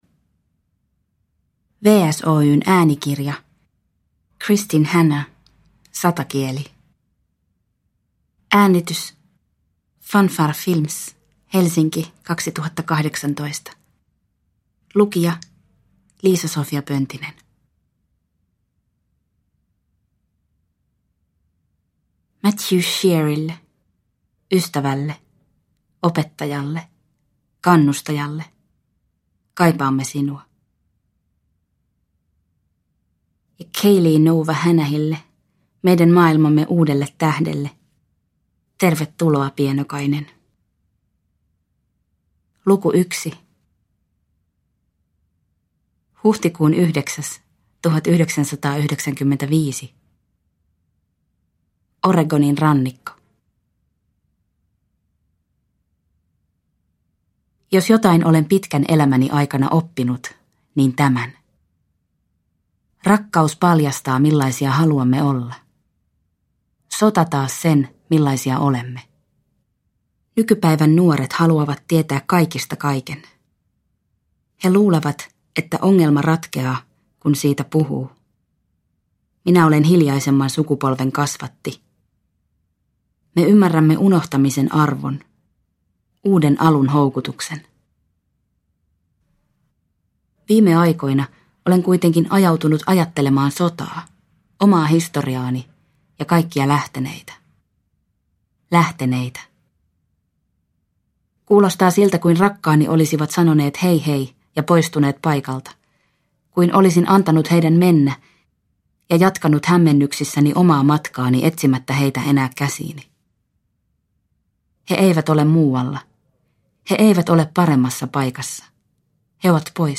Satakieli – Ljudbok – Laddas ner